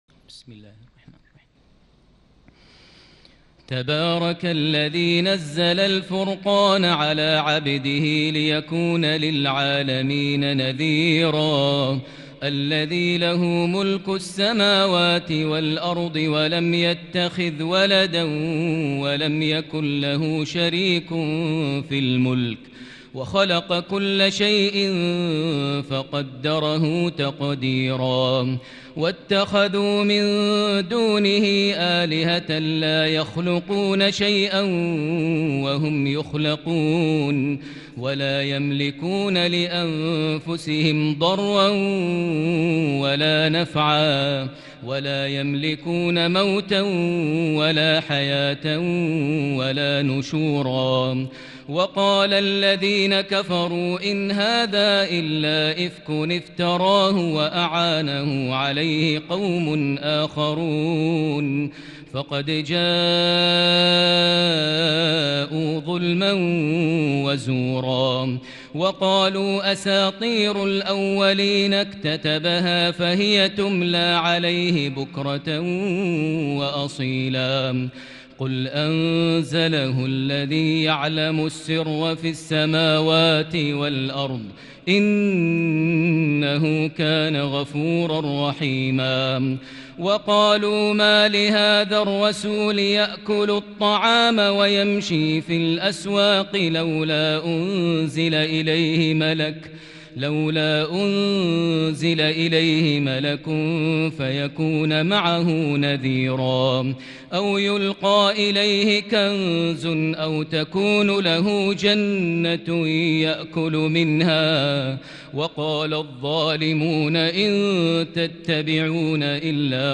تهجد ليلة 22 رمضان 1441هـ سورة الفرقان كاملة | Tahajjud prayer | The night of Ramadan 22 1441H | Surah Al-Furqan > تراويح الحرم المكي عام 1441 🕋 > التراويح - تلاوات الحرمين